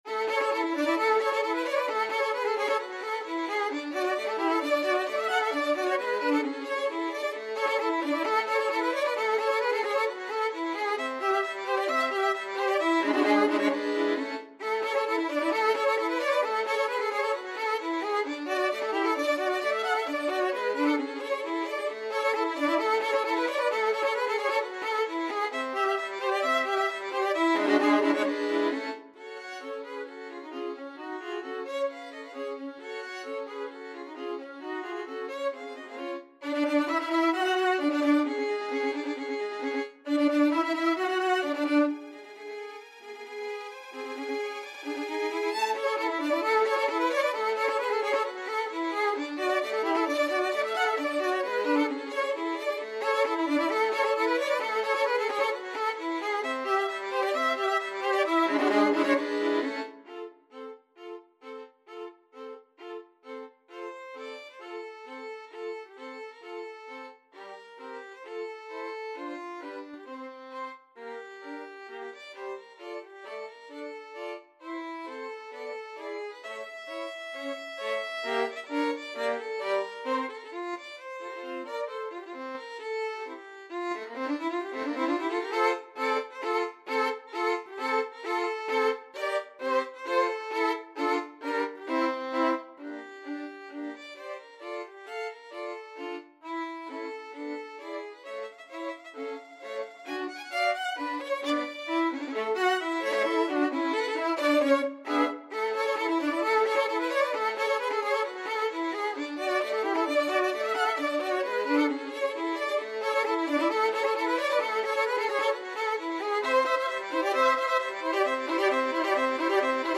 2/4 (View more 2/4 Music)
Violin Trio  (View more Intermediate Violin Trio Music)
Classical (View more Classical Violin Trio Music)